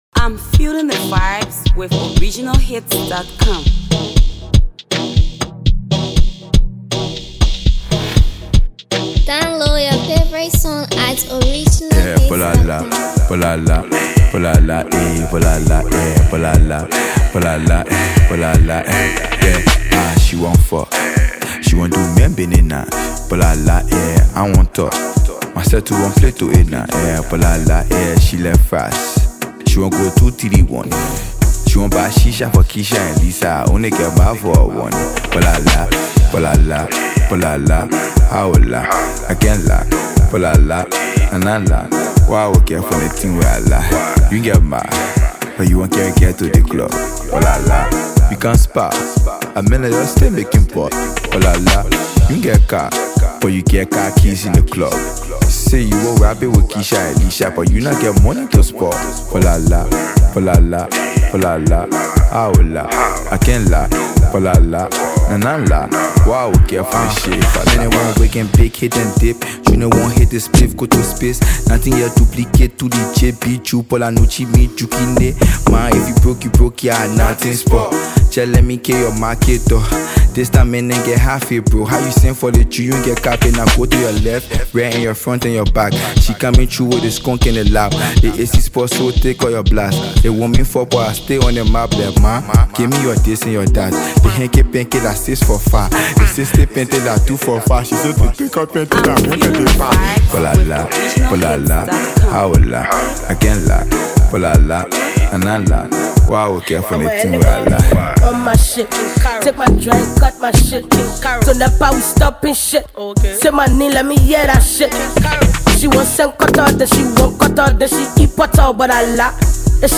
stimulating euphony sound
the rap game